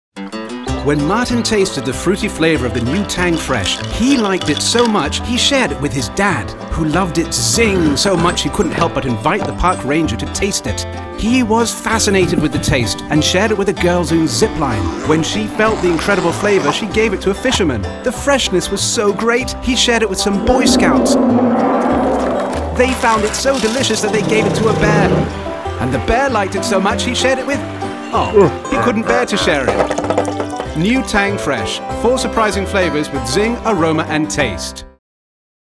excited, animated, fun